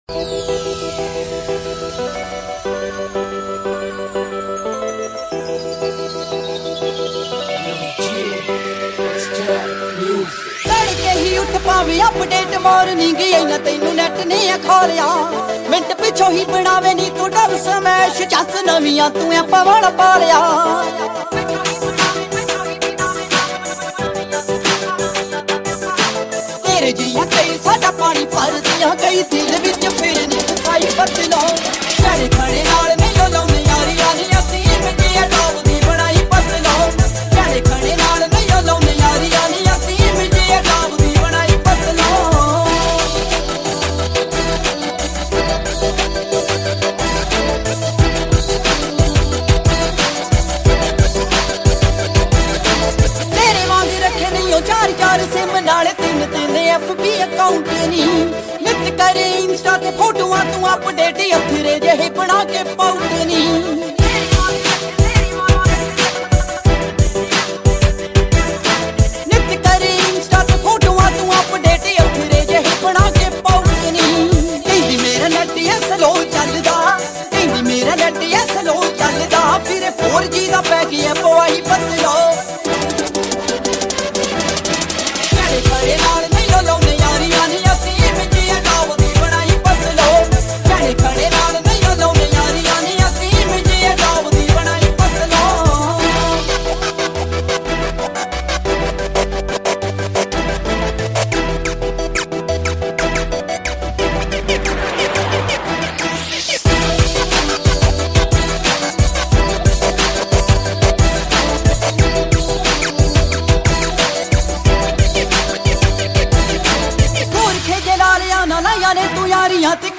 Punjabi Song